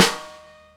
Twisting 2Nite Snr R Hand.wav